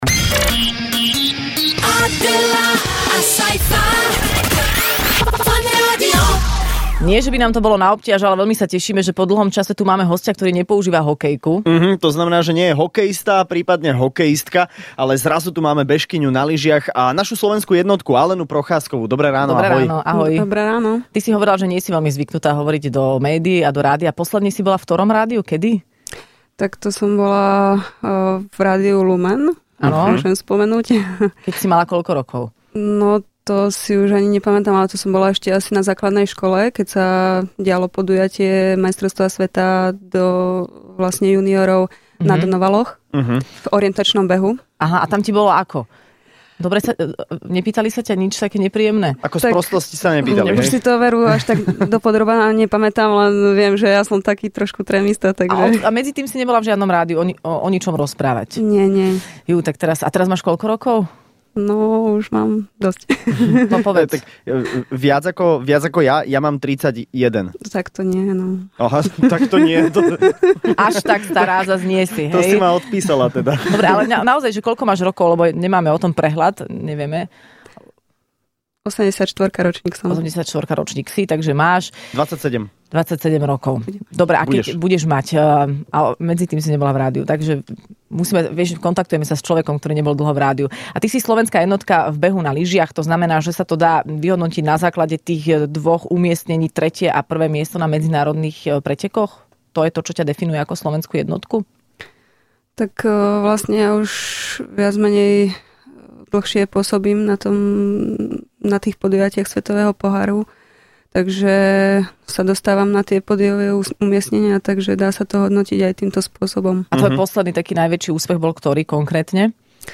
Hosťom v Rannej šou bola slovenská jednotka v behu na lyžiach - Alena Procházková